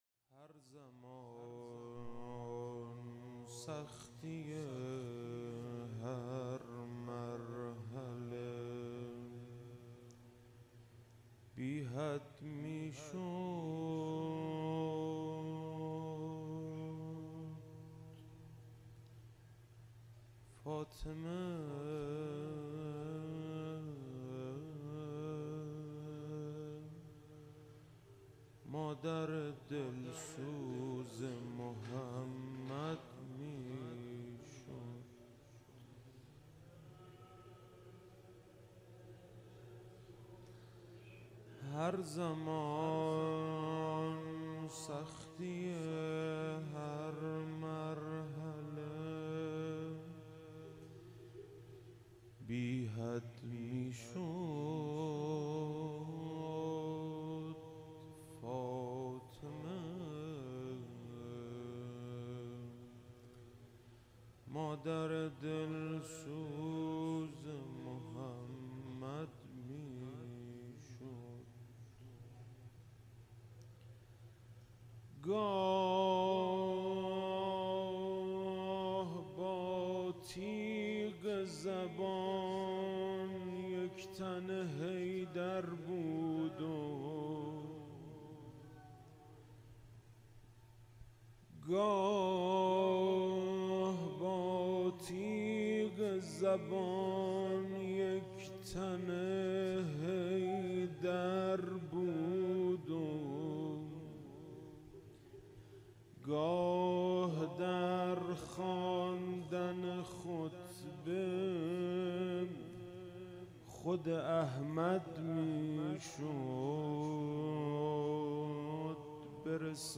فاطمیه 96 - شب پنجم - روضه